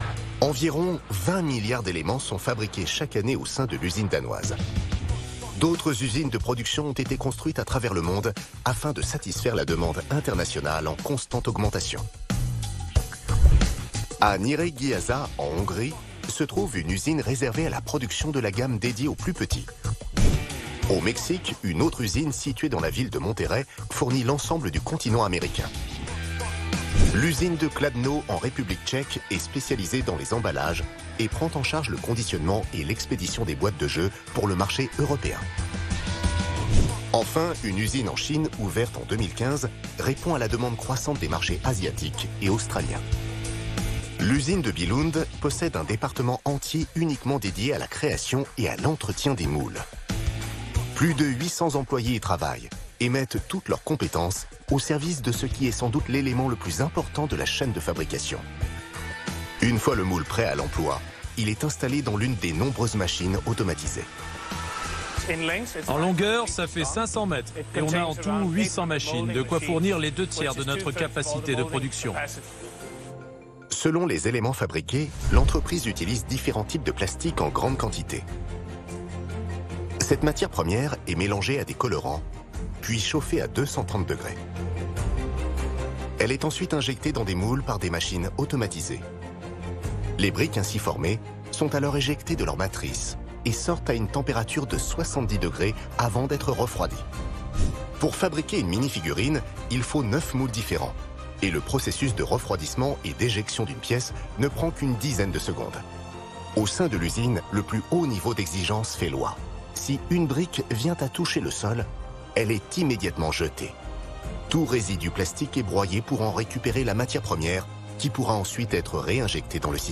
Extrait de narration convaincante sur le documentaire "Inside Lego".
Factuel et dynamique.
Voix off de narration enregistrée et mixée chez Planimonteur.
Ma narration devait être précise, assez enthousiaste. Ma voix se pose dans ces cas là sur un timbre médium grave et le rythme est assez soutenu.